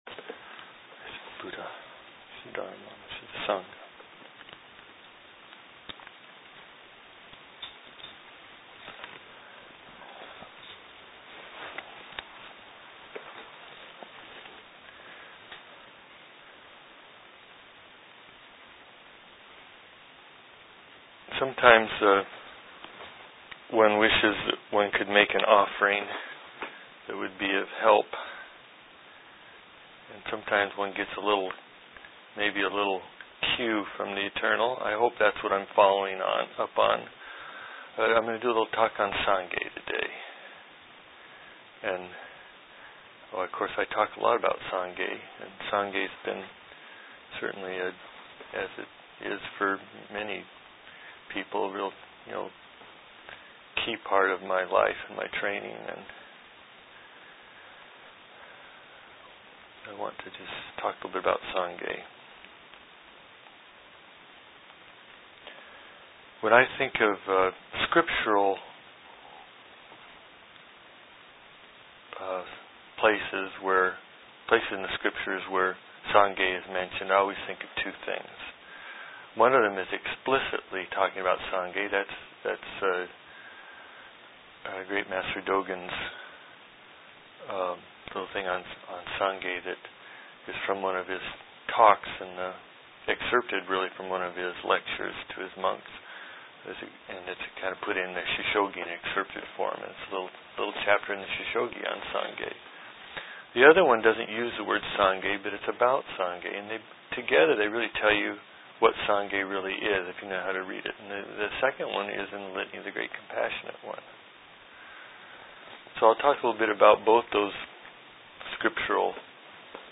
DHARMA TALKS —2011